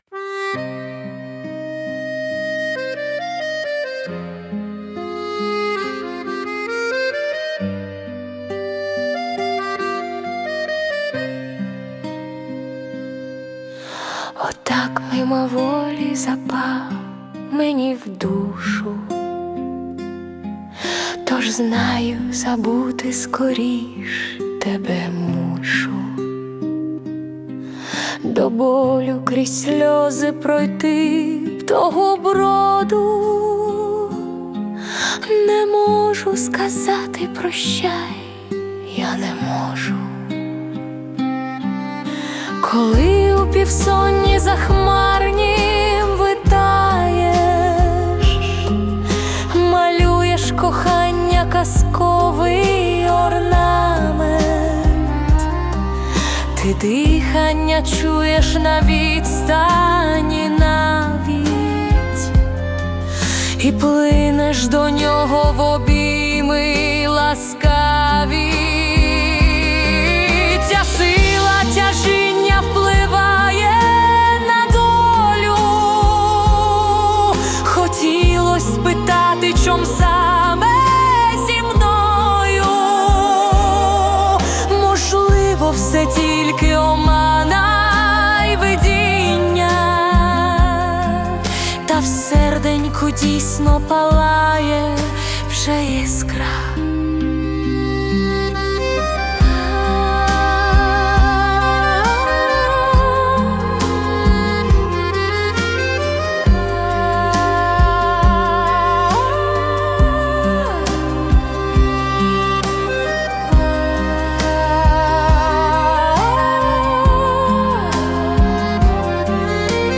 Музична композиція створена за допомогою SUNO AI